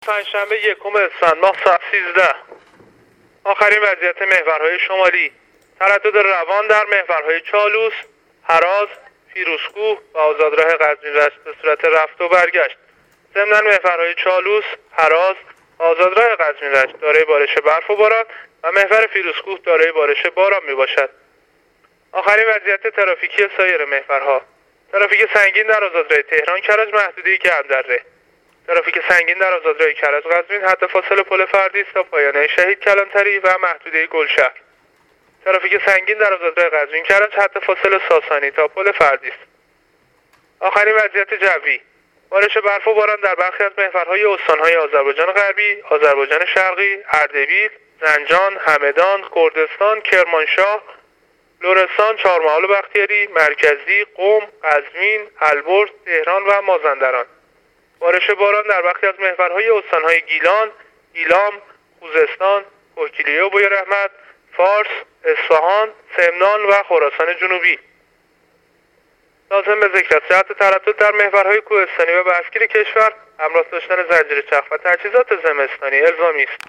گزارش رادیو اینترنتی از آخرین وضعیت ترافیکی جاده‌ها تا ساعت ۱۳ پنج‌شنبه یکم اسفند ۱۳۹۸